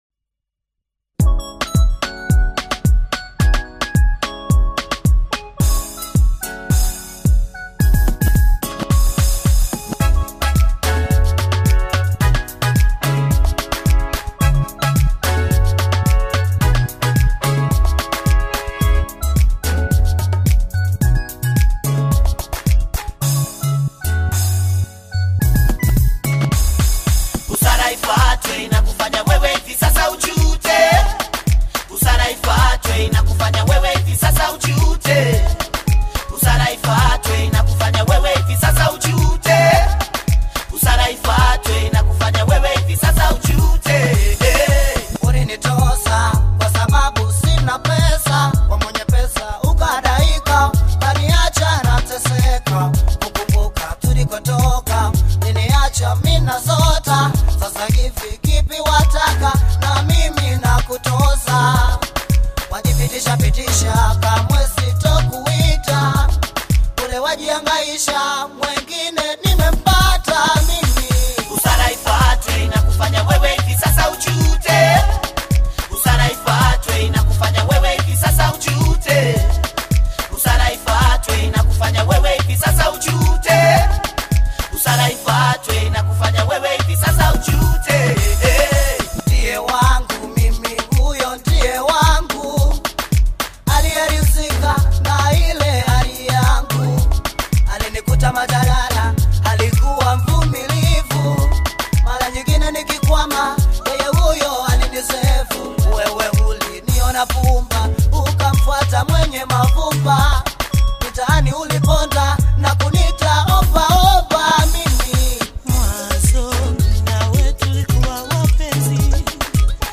is a vibrant Bongo Flava single